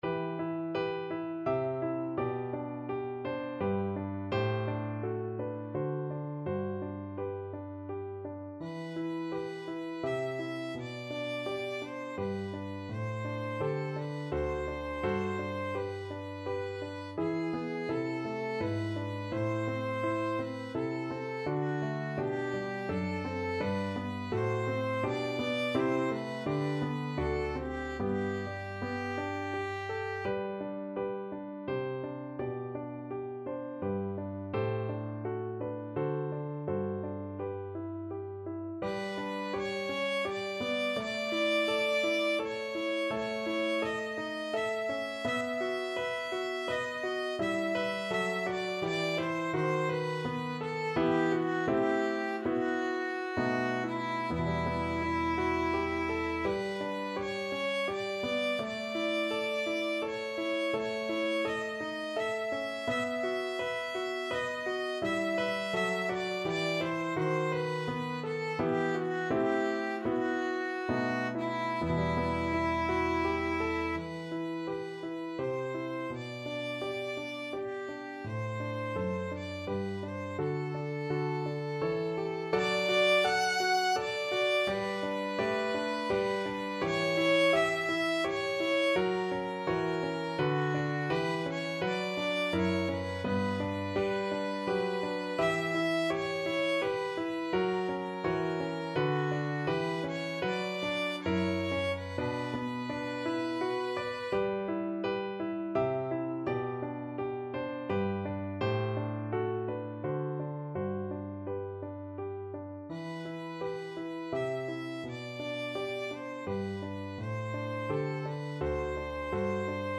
Classical Caldara, Antonio Sebben Crudele Violin version
Play (or use space bar on your keyboard) Pause Music Playalong - Piano Accompaniment Playalong Band Accompaniment not yet available transpose reset tempo print settings full screen
Violin
Italian Baroque composer.
3/4 (View more 3/4 Music)
E minor (Sounding Pitch) (View more E minor Music for Violin )
Allegretto grazioso = 84
Classical (View more Classical Violin Music)